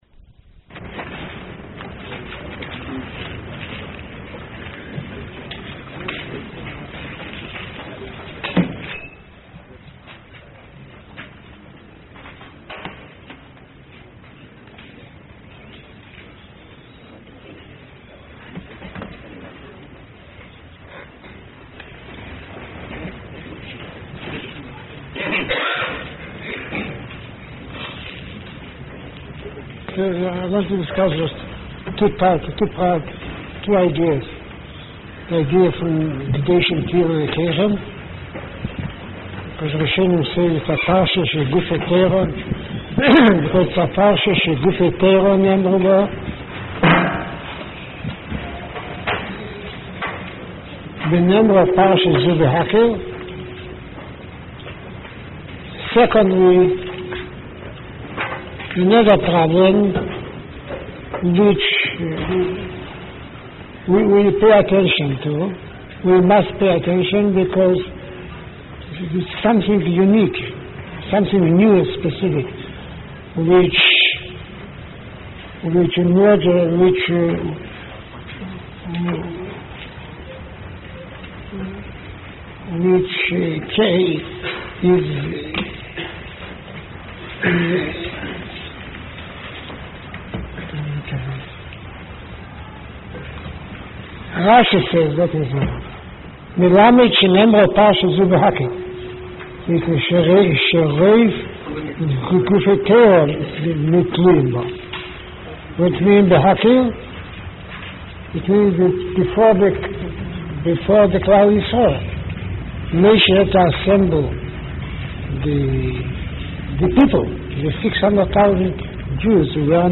Chumash Shiur YU 4/28/1981